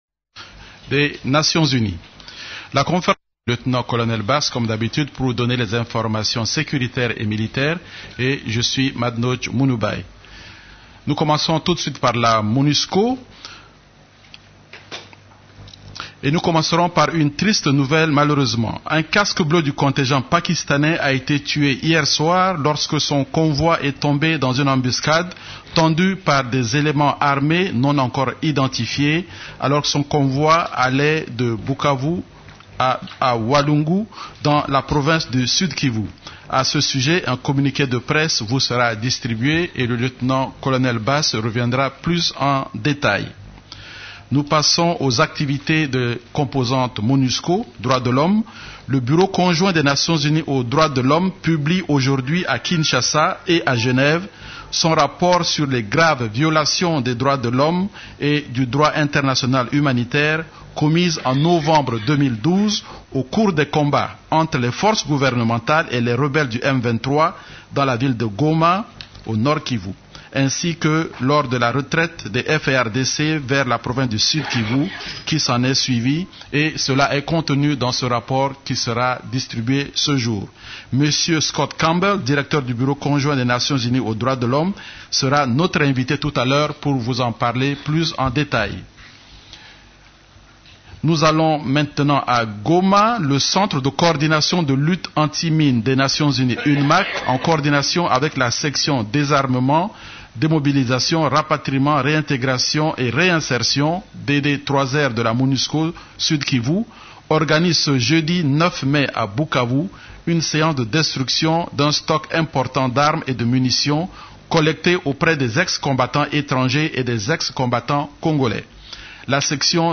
La conférence de presse hebdomadaire des Nations unies en RDC du mercredi 8 mai a porté sur les sujets suivants: